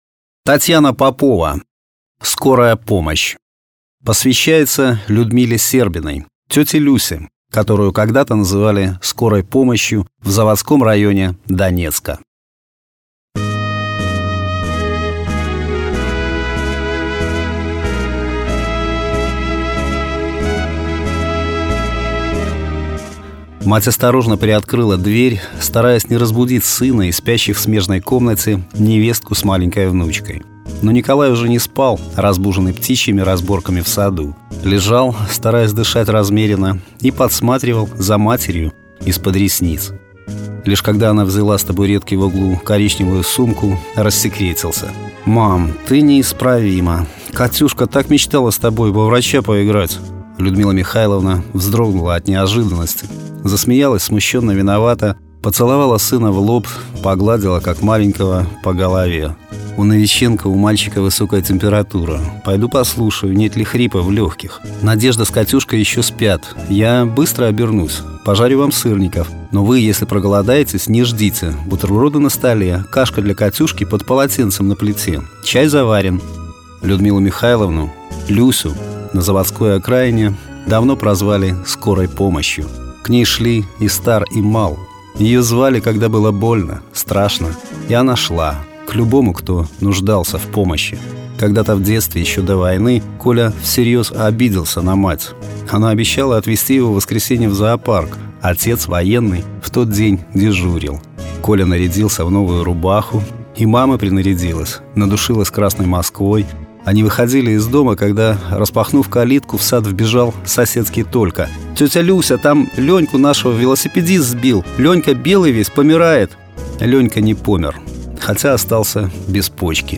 Аудиокниги
Аудио-Проза